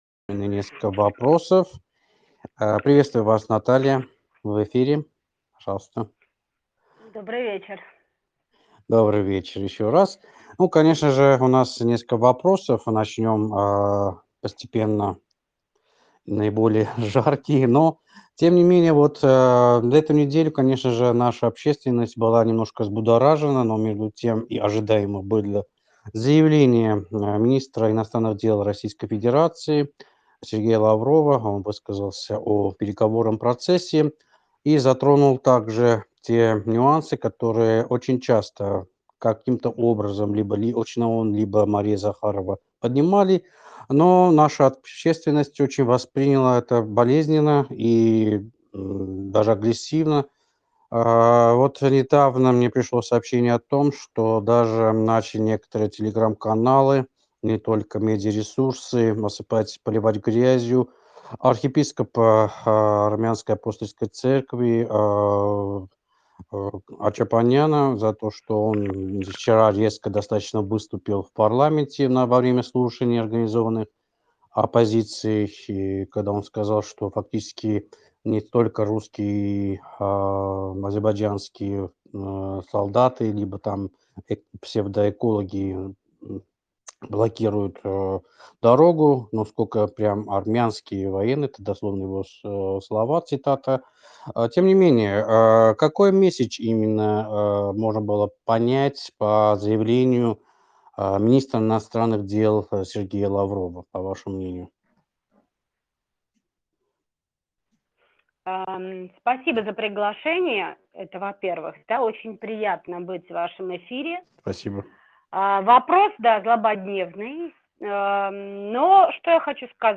ЗАПИСЬ ЭФИРА ЕЖЕНЕДЕЛЬНОГО ПОДКАСТА «ВЕЧЕРНИЙ ЕРЕВАН» от 4 февраля 2023 года